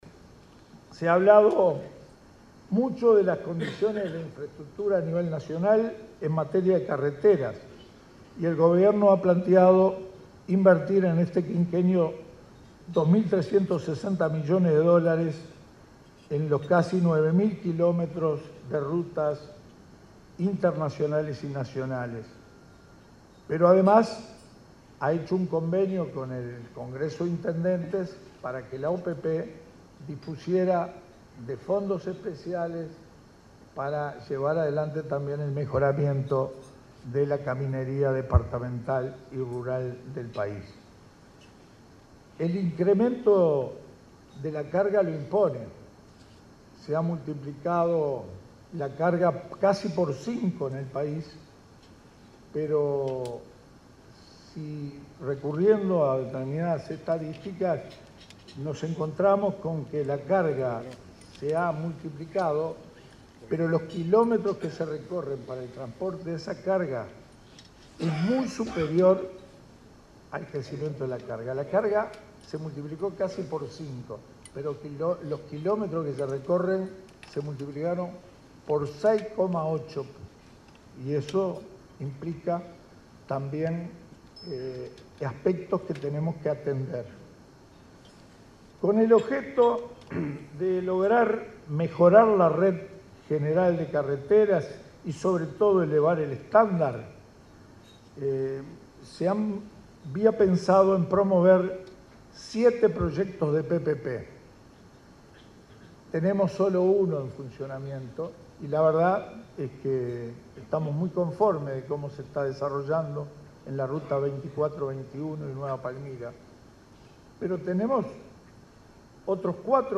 “La inversión en obras en rutas nacionales alcanzará los 2.360 millones de dólares en el quinquenio”, señaló el ministro de Transporte, Víctor Rossi, en un desayuno de trabajo de Somos Uruguay. Como parte del Plan Nacional de Infraestructura, el 23 de noviembre se inaugurarán obras en ruta 30 y, el 12 de diciembre, la doble vía de ruta 8 entre Pando y ruta 11.